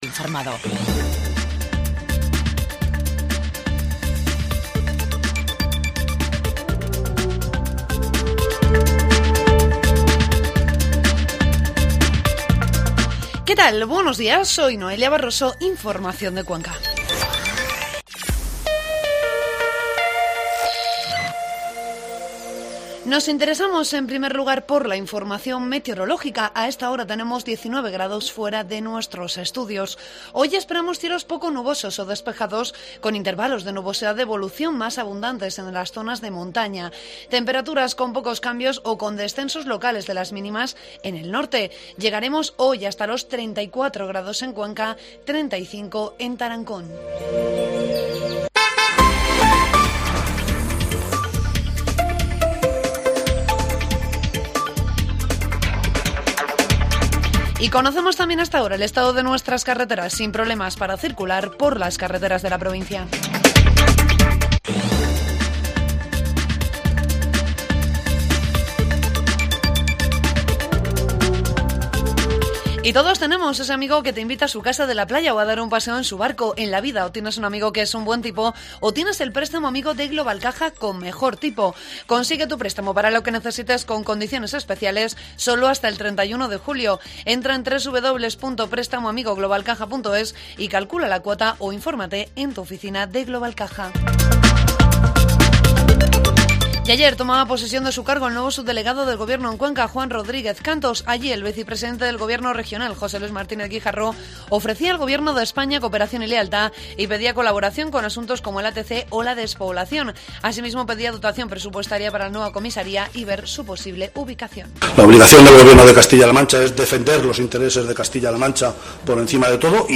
Informativo matinal COPE Cuenca 10 de julio